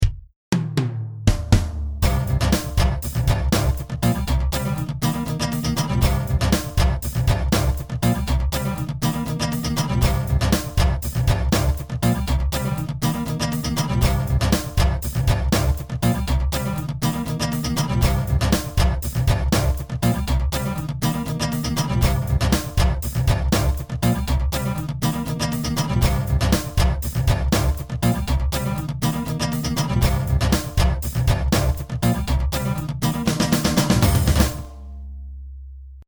A driving bucket beat!
• An audio backing track to play along with in class